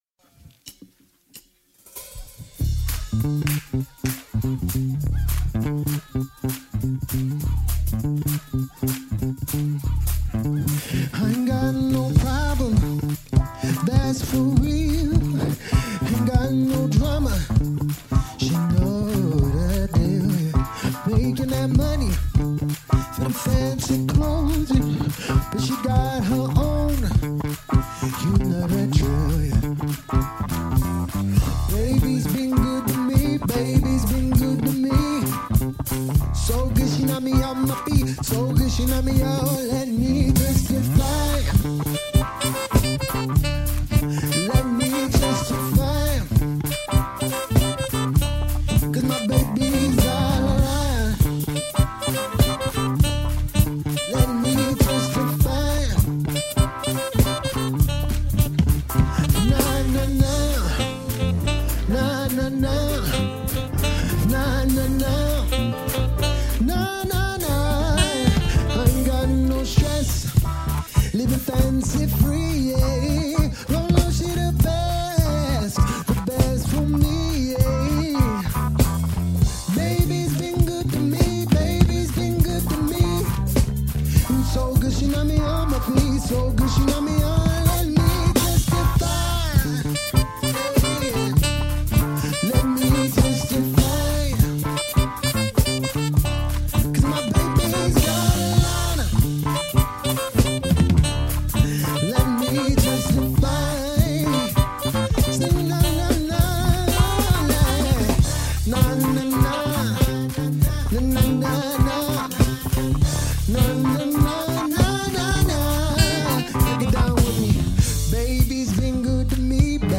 Quarantine Livestreams Genre: Disco